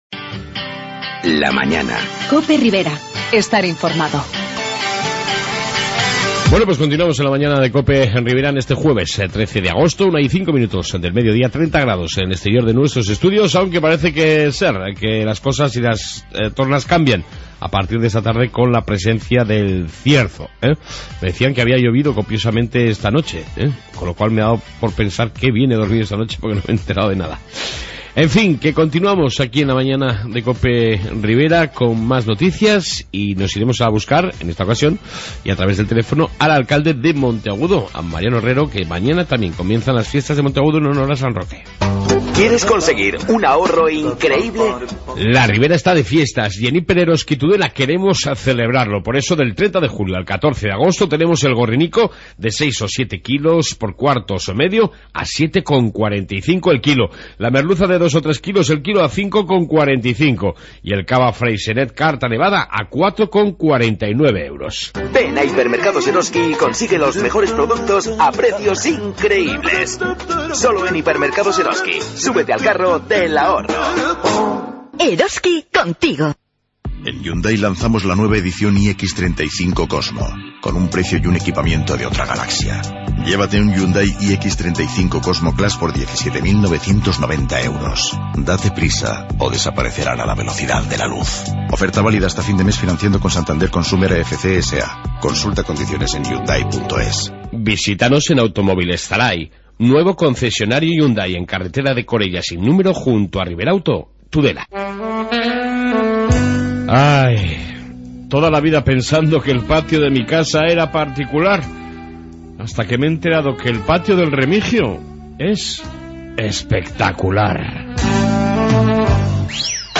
AUDIO: Noticias Riberas y entrevista con el Alcalde de Monteagudo sobre el inicio, mañana, de las Fiestas en honor a San Roque